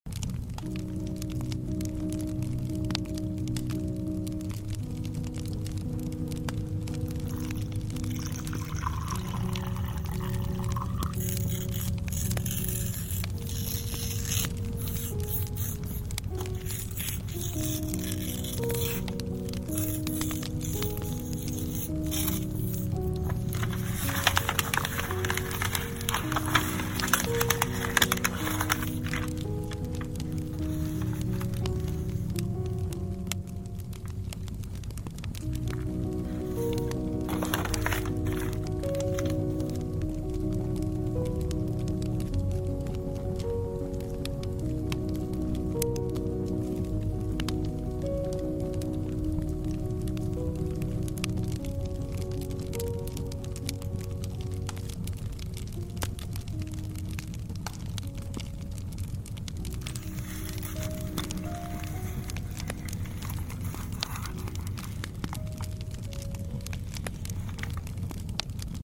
who else love the sound of shreding paper?